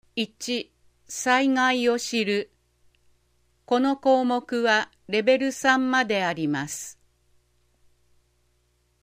豊中市総合ハザードマップ音訳版(1)1.災害を知る～3.大雨時のとるべき行動